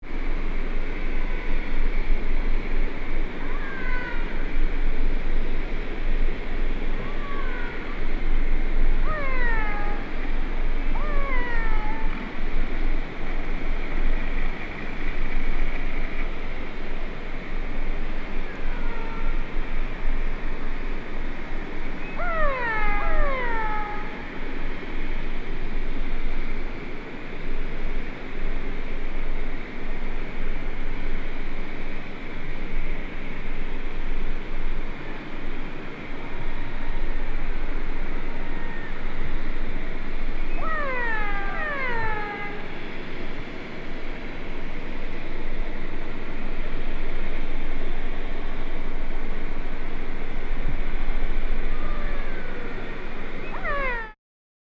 July 2019 #1 Orca Voices
Plaintive meowing calls greet her ears – the signature calls of G clan, Northern Resident Killer Whales!
It’s a whole conference of calls.
It sounds like he is calling to them and they are answering – contact calls in a world of murky water and intrusive motor noise.
Bull-Orca-Calls-G-Clan.mp3